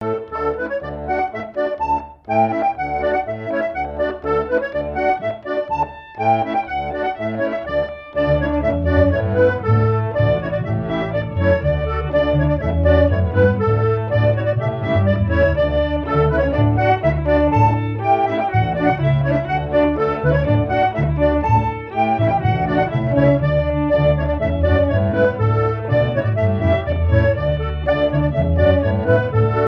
Marais Breton Vendéen
danse : branle : courante, maraîchine
Pièce musicale éditée